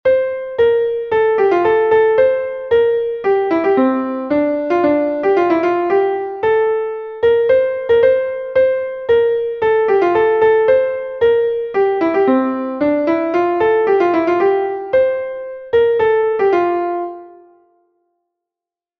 Cal é a forma deste fragmento? audio/mpeg Respostas Opción 1 A A Opción 2 A A´ Opción 3 A B Retroalimentación Incorrecto Son dúas frases de 8 compases, que son iguais nos primeiros 4, e varían un pouco nos outros 4.